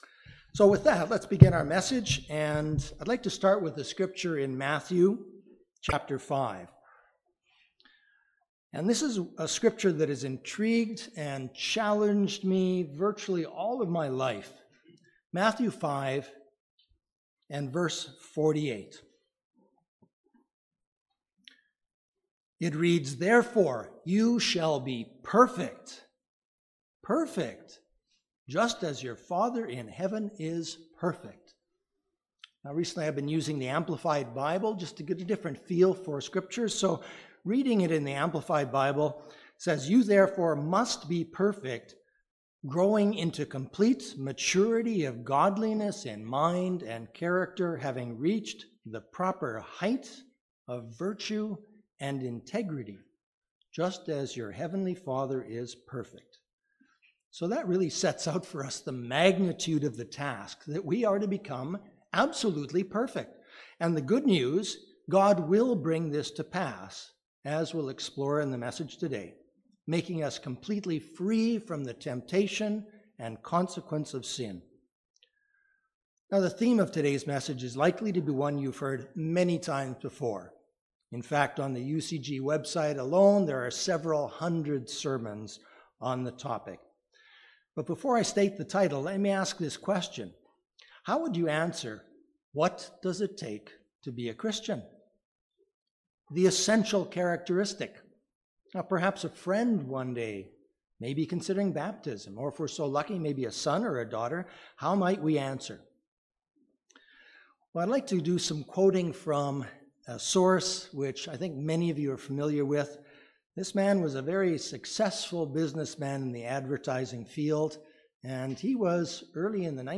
Given in Northwest Indiana